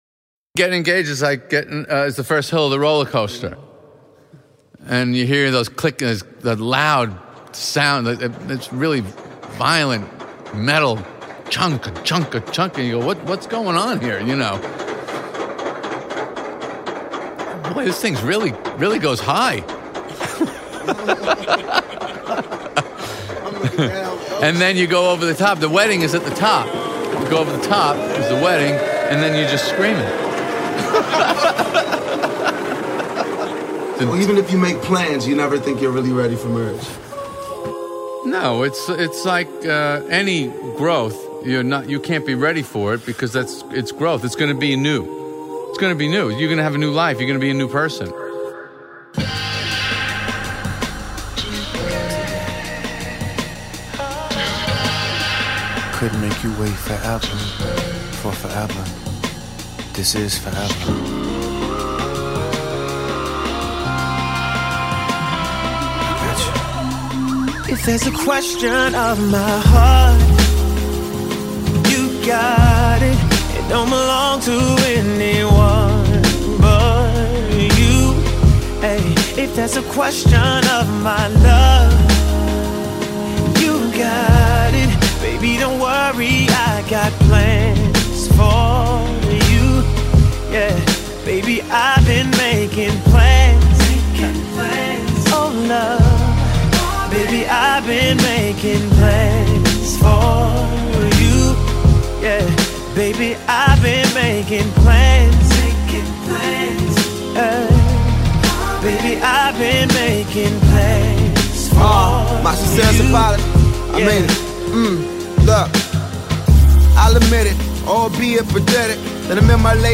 Keeping you upfront and in-front of the latest R&B jams.